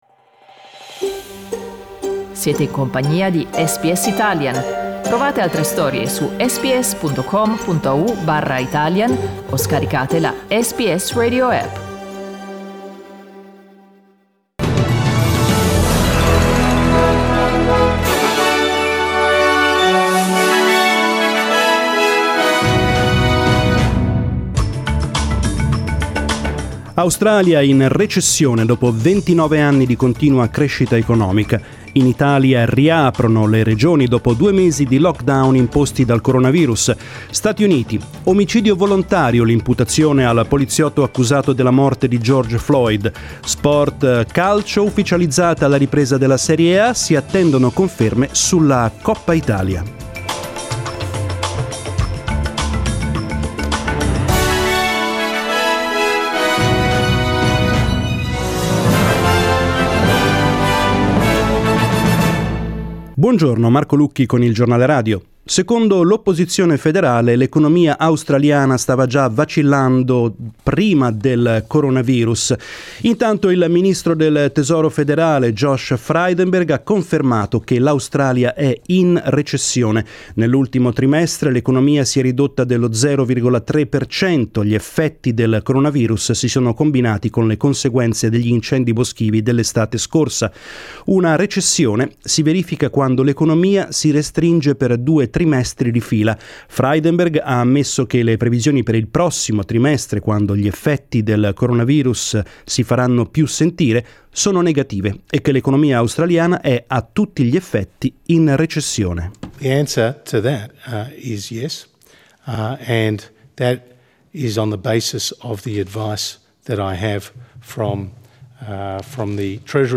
News in Italian 4/6/2020